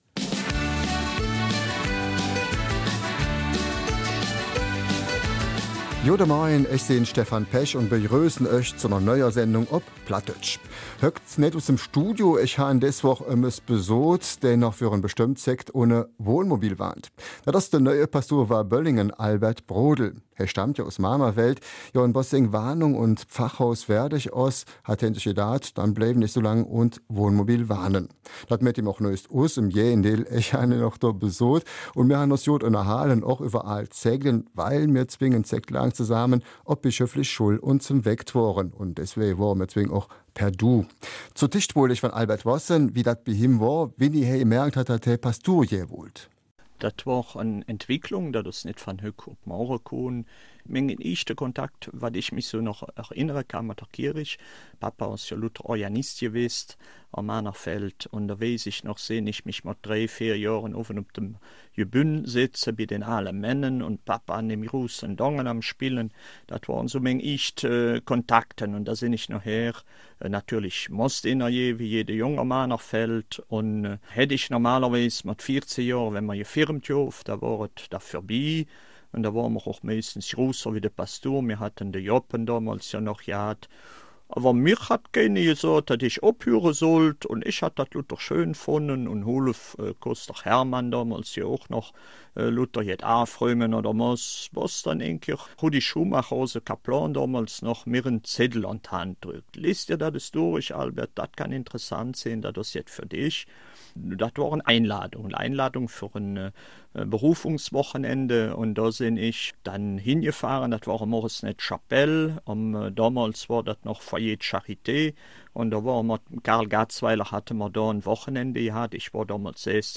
Eifeler Mundart: Seelsorge aus dem Wohnmobil
BRF-Mundart hat ihn dort besucht und sprach mit ihm über seine priesterliche Laufbahn, die Rückkehr in die Heimatgemeinde und natürlich über sein Zuhause auf Zeit.